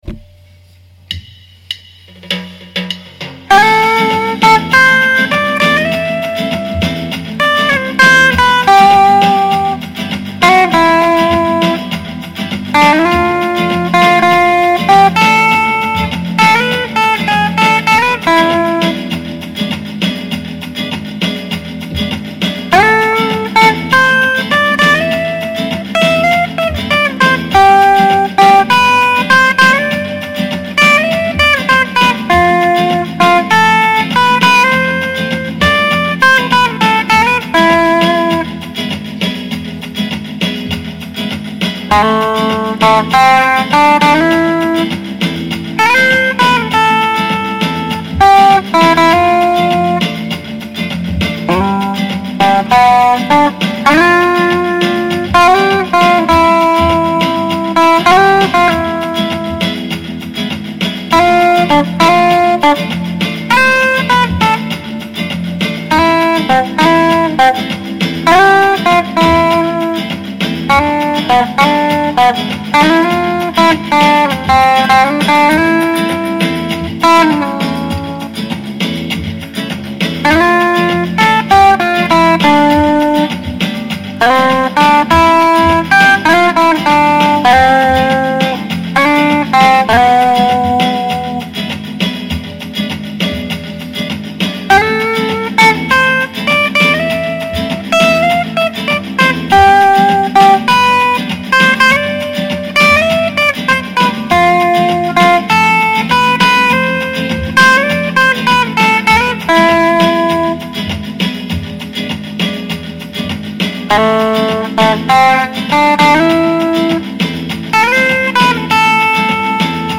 Pop Flamenco